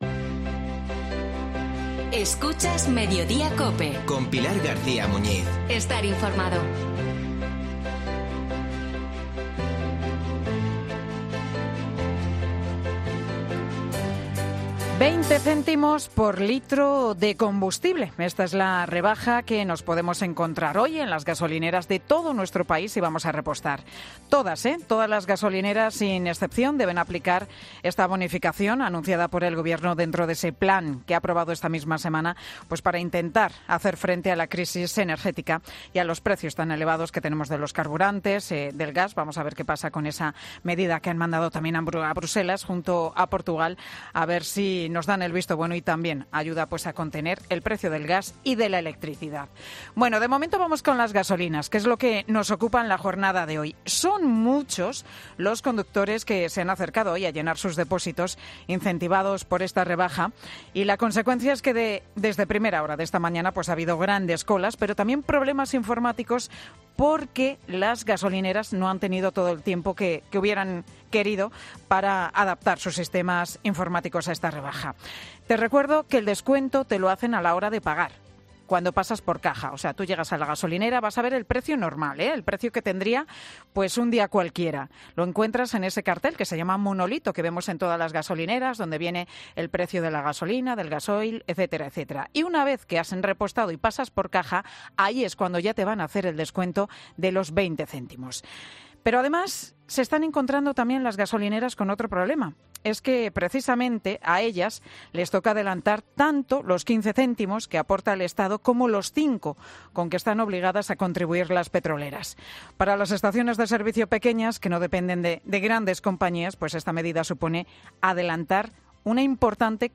En Mediodía COPE hemos hablado con varias gasolineras de nuestro país para ver cuál es la situación.